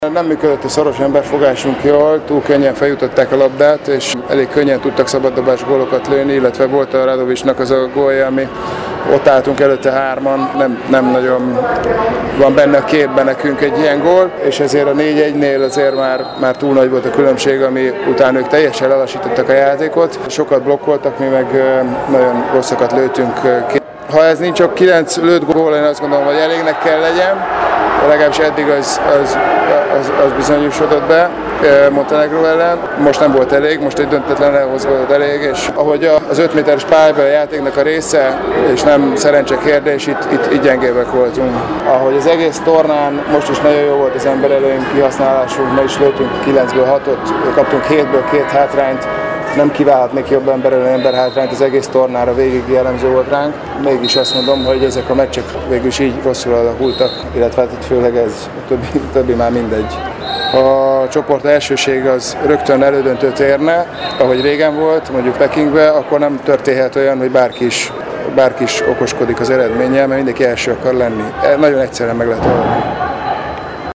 Benedek Tibor magyar szövetségi kapitány szerint az első negyedben nagyon gyenge volt a csapat, és pozitívum, hogy sikerült visszajönni a játékba, de ennek ellenére a vége nem úgy alakult, ahogy kellett volna: